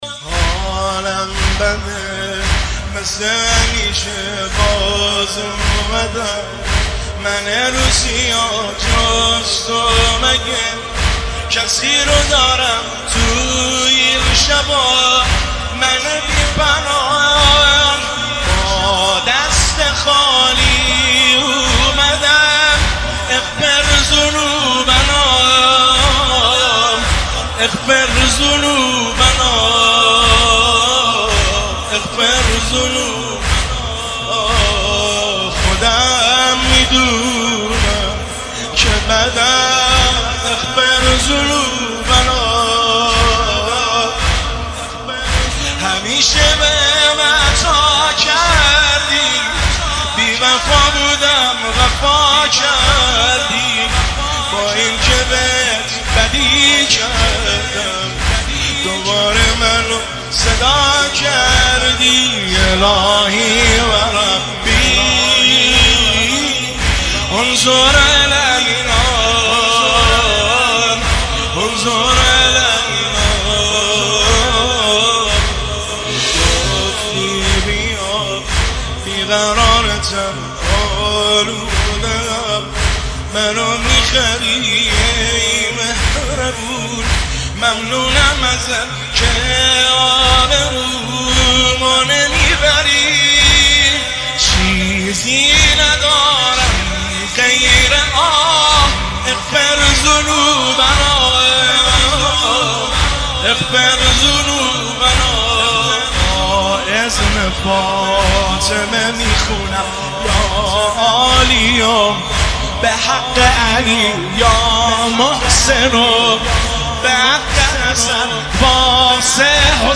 زمینه – شب 20 رمضان المبارک 1399